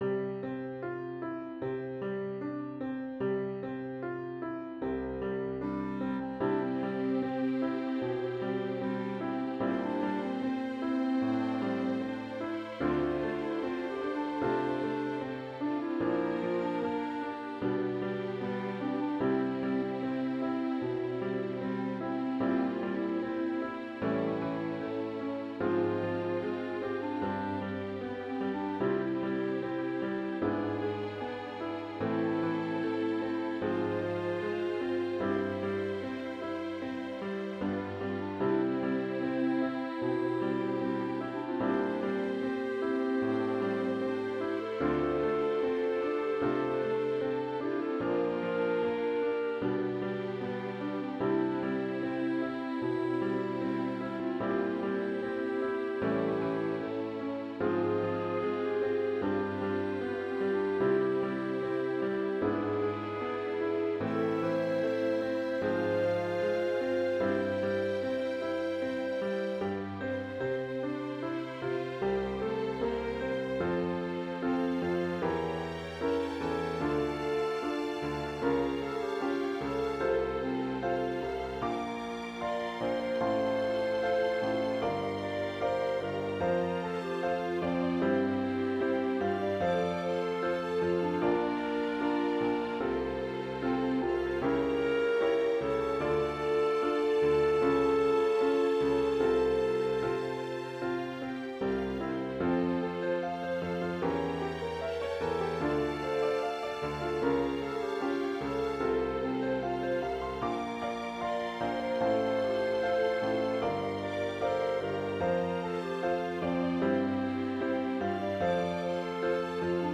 + počítačové verzie niektorých našich úprav 😉